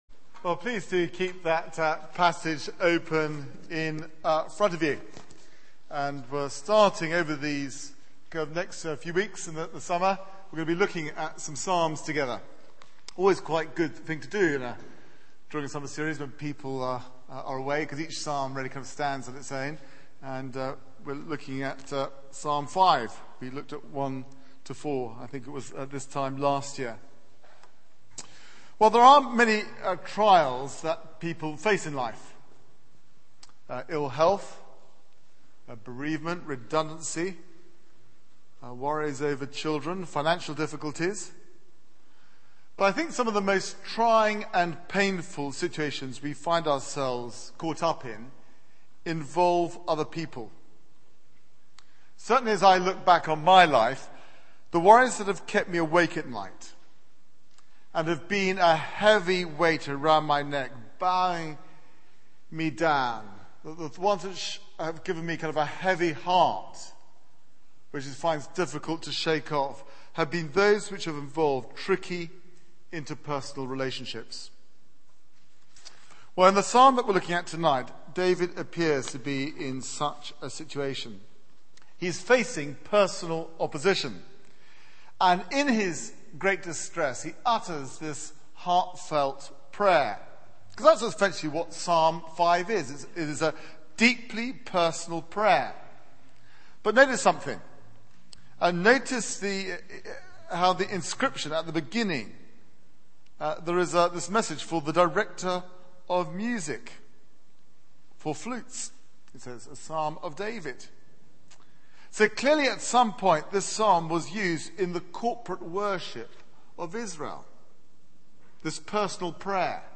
Media for 6:30pm Service on Sun 25th Jul 2010 18:30 Speaker
Summer songs Theme: The God who helps Sermon Search the media library There are recordings here going back several years.